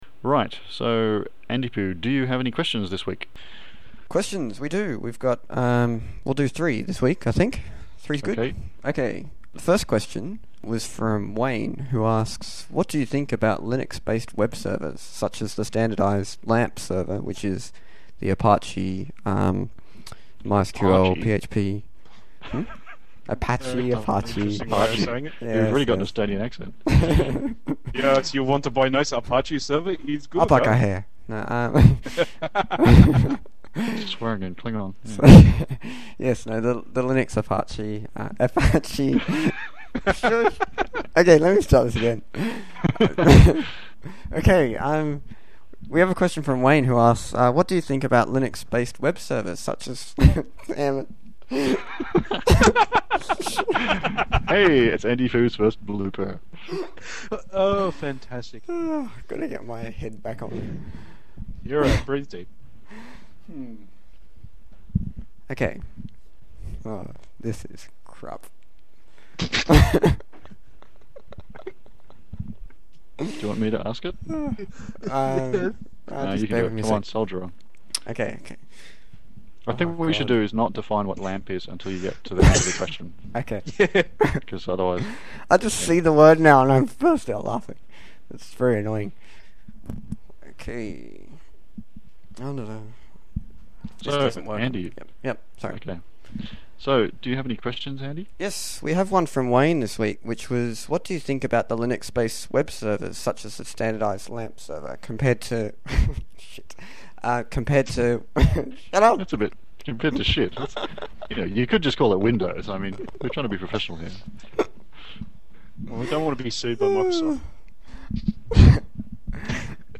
Bloopers